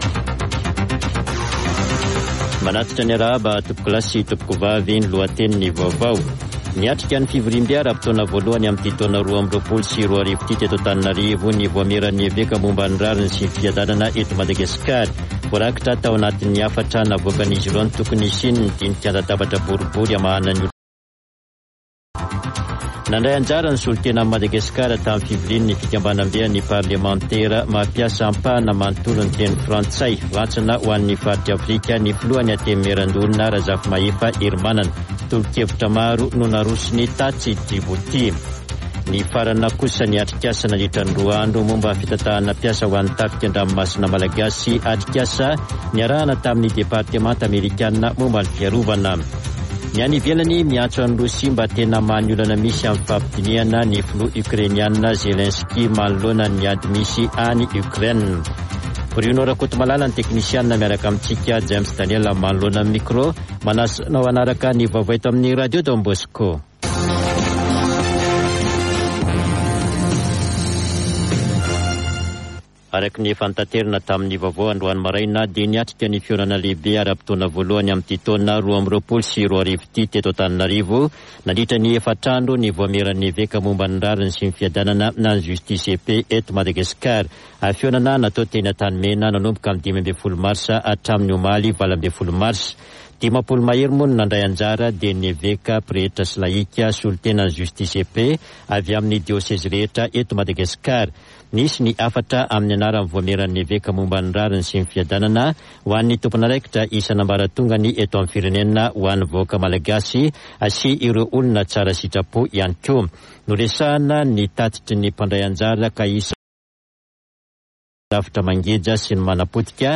[Vaovao antoandro] Sabotsy 19 marsa 2022